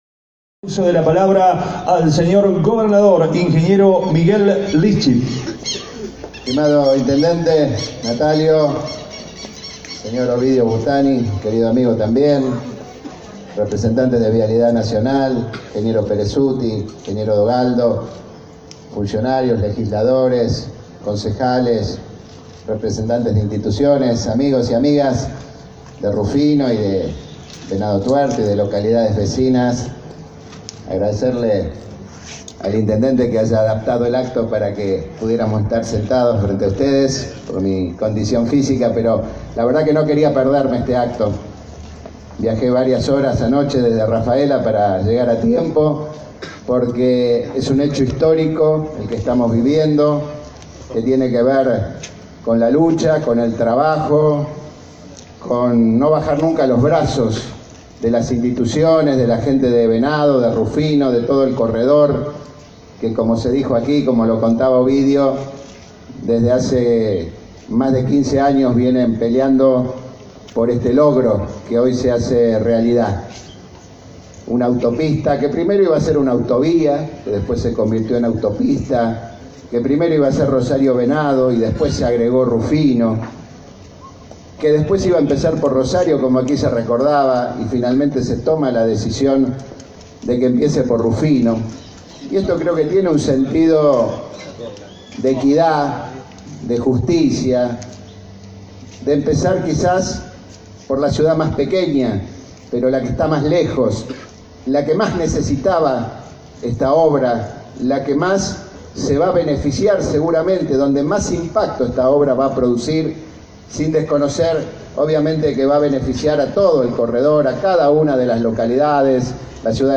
El gobernador Miguel Lifschitz participó este jueves en la ciudad de Rufino, del acto en el que se conocieron tres ofertas económicas para la construcción de la primera etapa de la autopista Rosario–Rufino, obra que demandará una inversión de 3.700 millones de pesos.
Gobernador Miguel Lifschitz